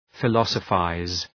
Προφορά
{fı’lɒsə,faız}